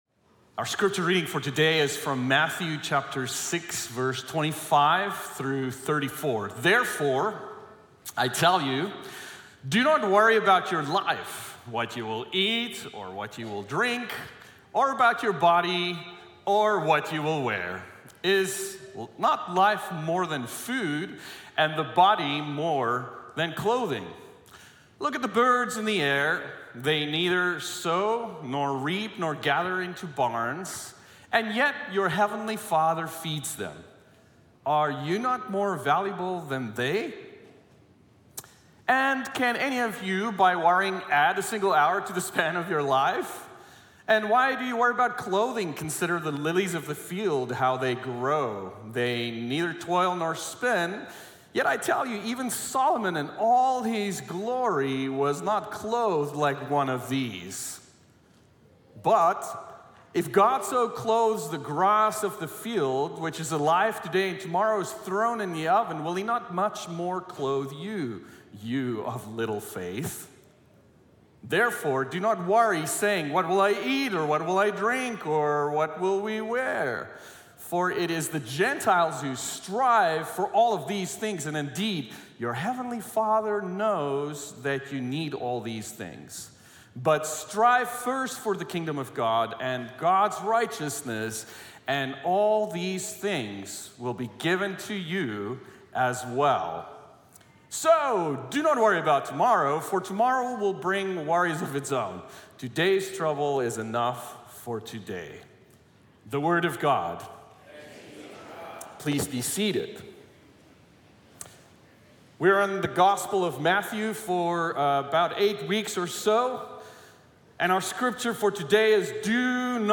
Sermon Speaker Archive | La Sierra University Church